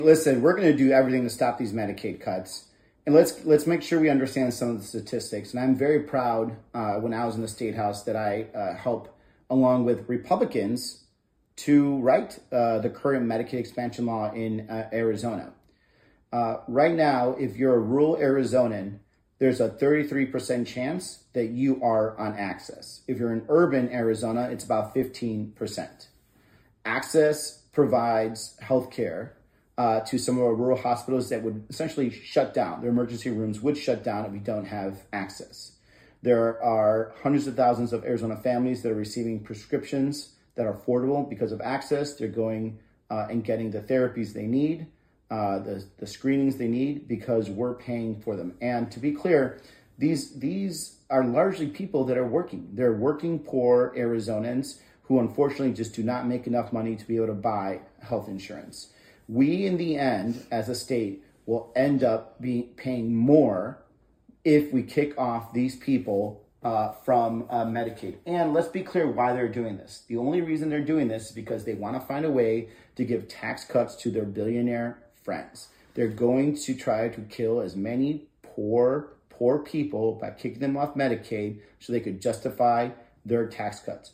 PHOENIX – Last night, Senator Ruben Gallego (D-AZ) spoke to over 3,000 Arizonans during his first telephone town hall.
On the call, Senator Gallego spelled out exactly what that would mean for Arizonans.